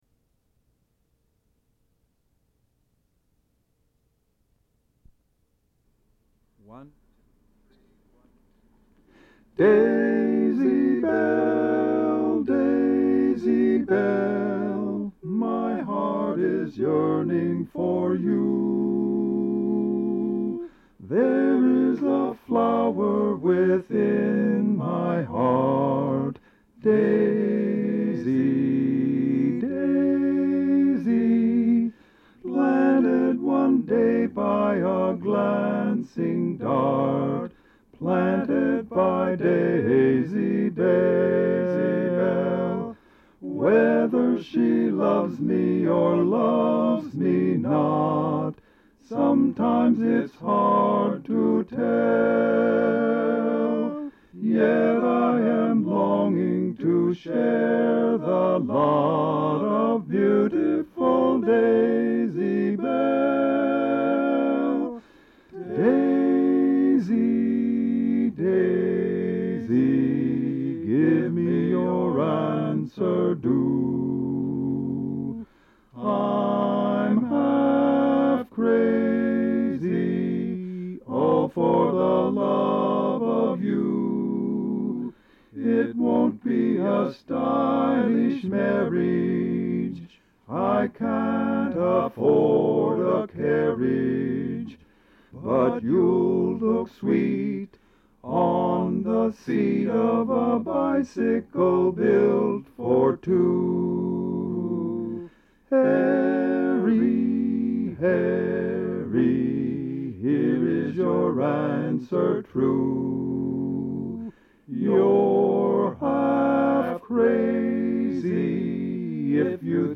Having already put two quartets of my own together I learned all 4 parts, returned to the studio.
as a quartet
singing with himself in four parts